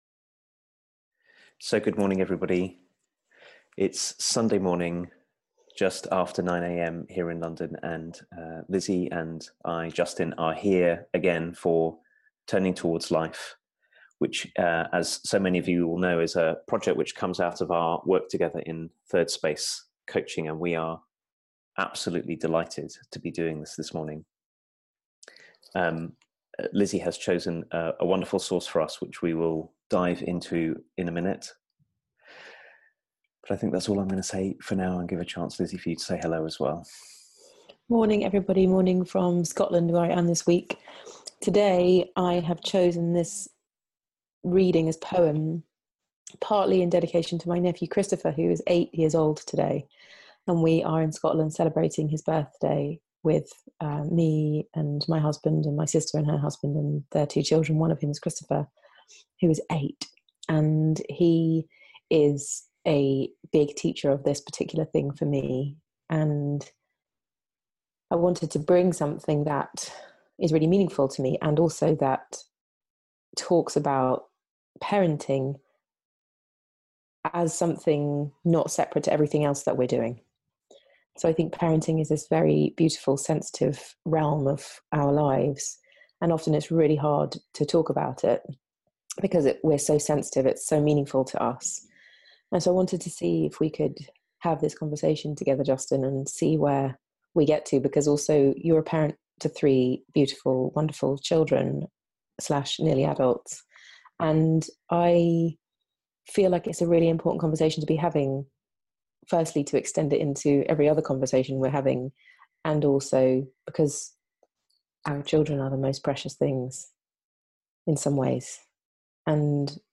Turning Towards Life is a weekly live 30 minute conversation